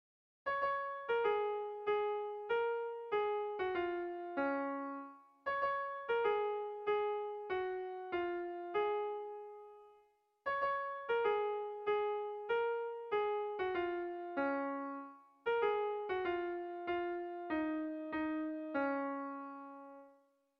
Sentimenduzkoa
Lauko handia (hg) / Bi puntuko handia (ip)
A1A2